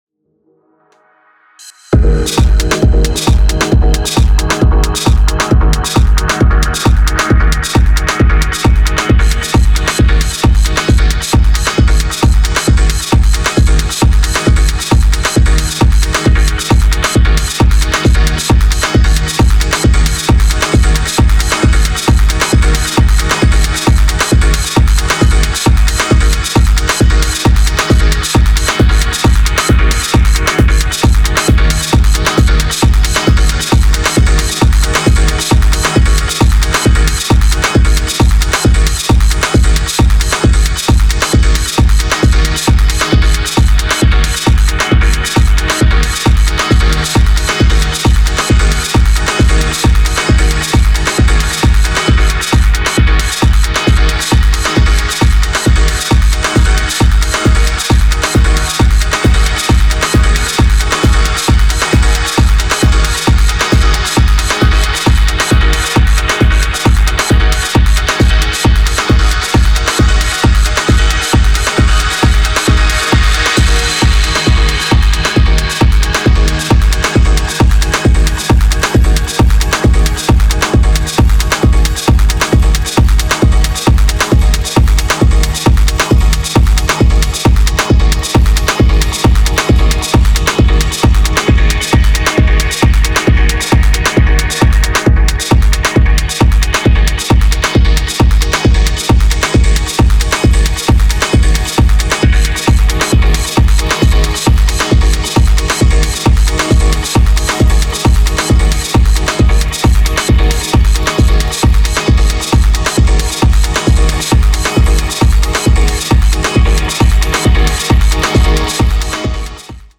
Detroit esque bangers!
Techno